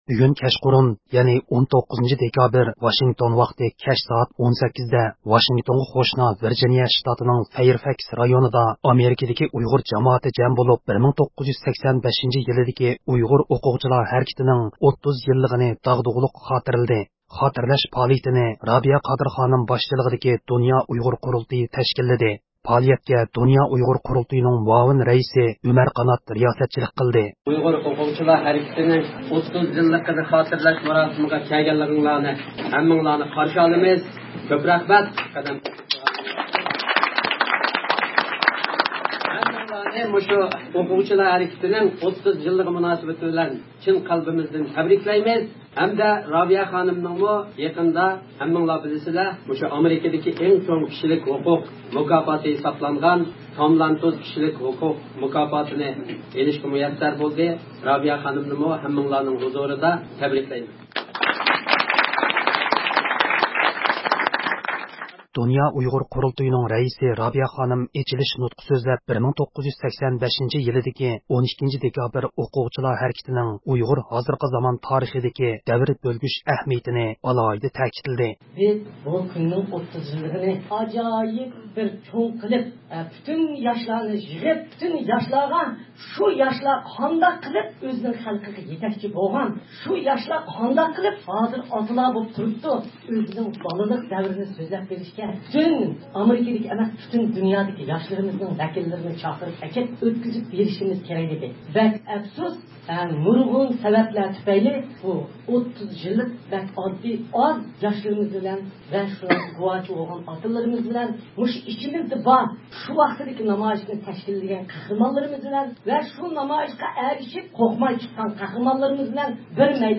بۇ مۇناسىۋەت بىلەن 19 – دېكابىر كەچ سائەت 18:00 دە ۋاشىڭتونغا قوشنا ۋىرگىنىيە شىتاتىنىڭ فايىرفاكس رايونىدا ئامېرىكىدىكى ئۇيغۇر جامائىتى بىر يەرگە جەم بولۇپ، 1985 – يىلىدىكى 12 – دېكابىر ئۇيغۇر ئوقۇغۇچىلار ھەرىكىتىنىڭ 30 يىللىقىنى داغدۇغىلىق خاتىرىلىدى.
دۇنيا ئۇيغۇر قۇرۇلتىيىنىڭ رەئىسى رابىيە خانىم ئېچىلىش نۇتقى سۆزلەپ 1985 – يىلىدىكى 12 – دېكابىر ئۇيغۇر ئوقۇغۇچىلار ھەرىكىتىنىڭ ئۇيغۇر ھازىرقى زامان تارىخىدىكى دەۋر بۆلگۈچ ئەھمىيىتىنى ئالاھىدە تەكىتلىدى.
پائالىيەت داۋامىدا 1985 – يىلىدىكى ئۇيغۇر ئوقۇغۇچىلار نامايىشىنى تەشكىللەشتە مۇھىم رول ئوينىغان، ھازىر ئامېرىكا ۋە كانادادا ياشاۋاتقان 3 نەپەر شاھىت ئايرىم – ئايرىم سۆز قىلدى.